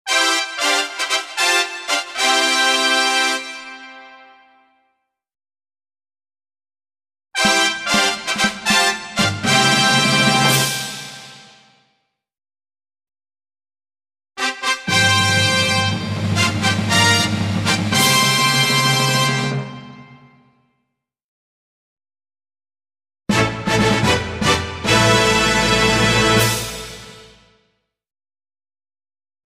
Фанфары придают вес событию и значимость.
Звук на начало награждения